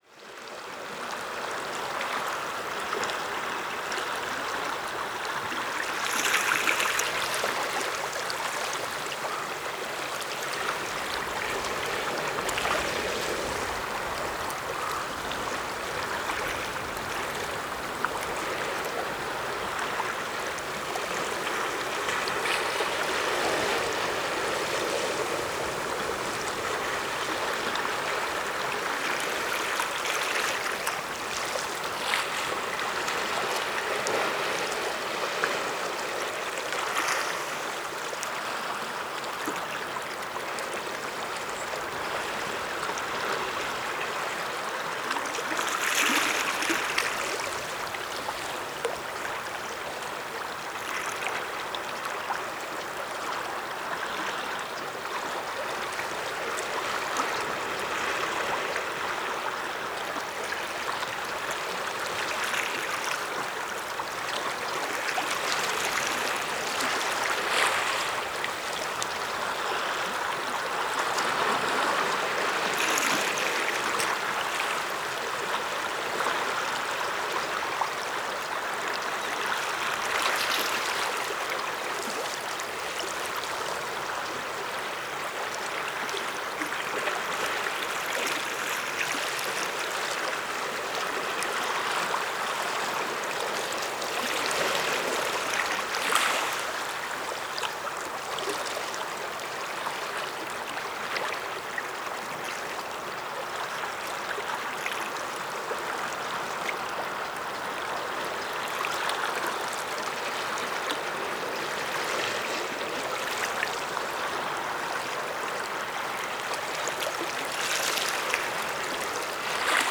屋久島 - 塚崎タイドプール 10:12am